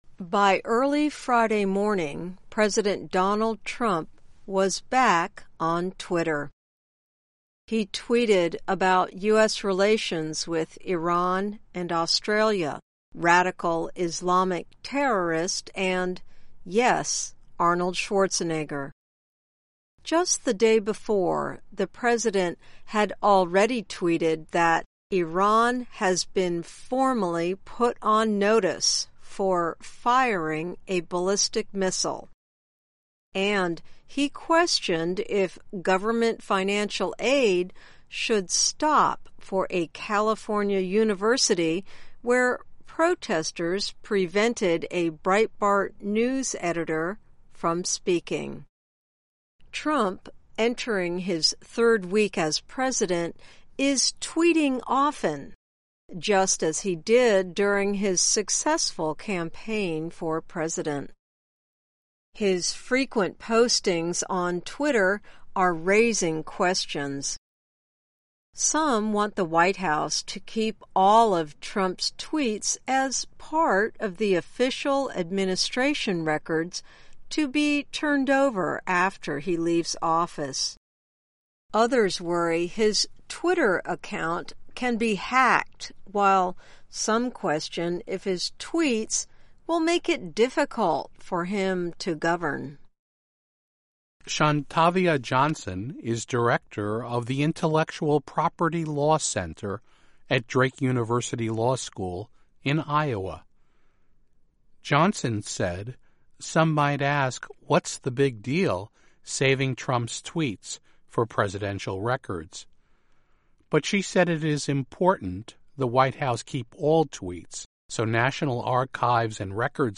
慢速英语:Why Some Worry About Trump’s Tweets